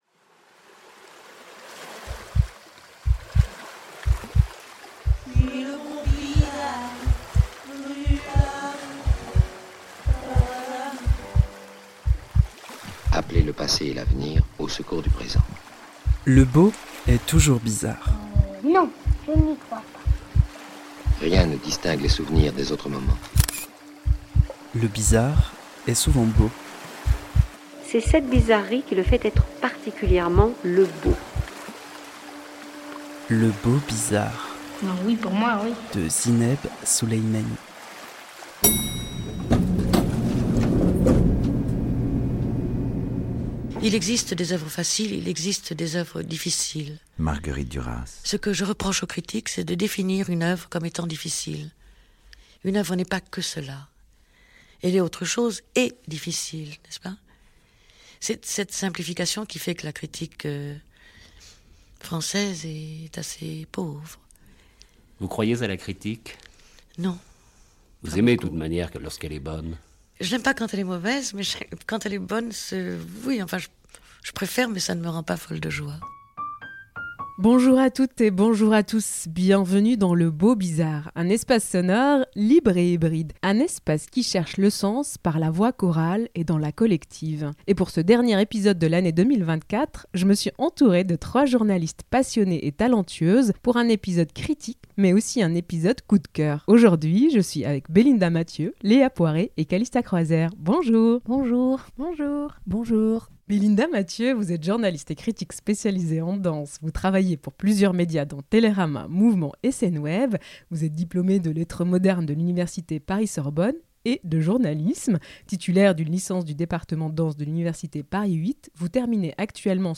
À 3 min : La séquence archive critique À partir d’archives, nous explorons les multiples visages de la critique : est-elle un exercice de subjectivité assumée ?